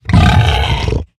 sounds / mob / hoglin / angry5.ogg
angry5.ogg